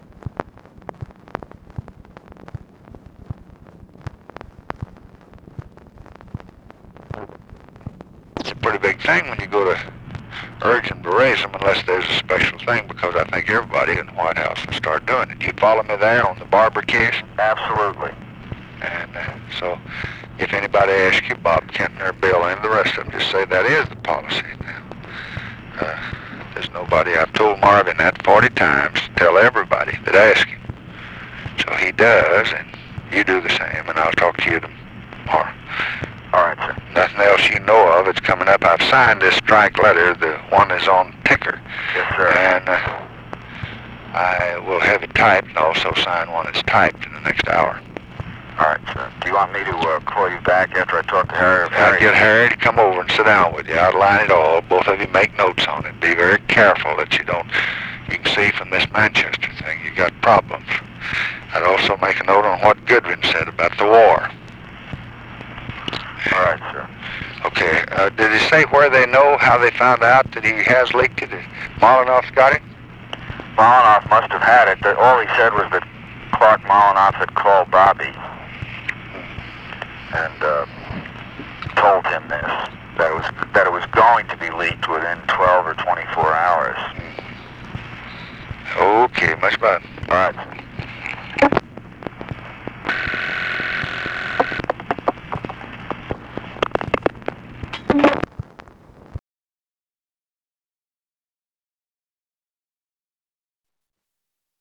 Conversation with JOSEPH CALIFANO, December 21, 1966
Secret White House Tapes